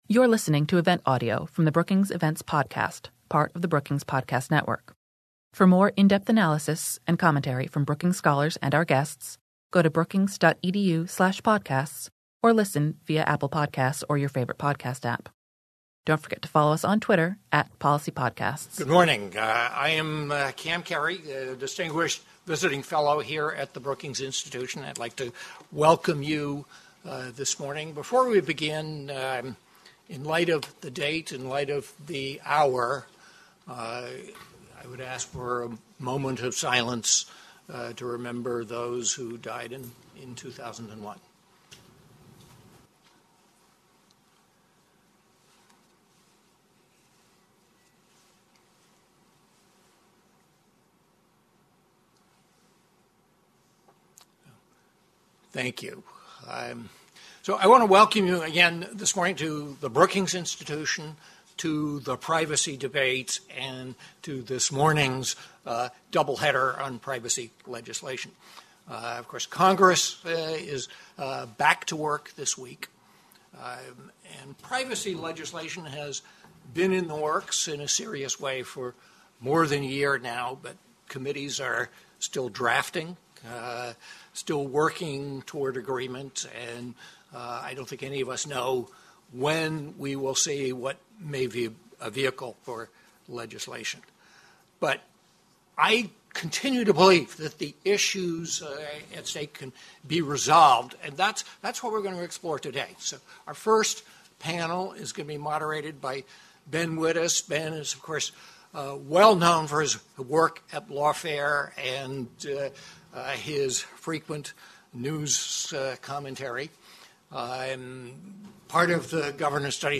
On September 11, the Center for Technology Innovation at Brookings hosted a public discussion on the status of federal privacy legislation. Panelists examined competing visions of what legislation should look like and assess the path forward to passage.
The first panel, which will be a live taping of the Lawfare Podcast, looked through the broad lens of competing visions of what legislation should accomplish and the impact on businesses and individuals.
Following the discussion, each panel answered questions from the audience.